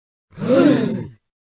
BFDI gasp
bfdi-gasp.mp3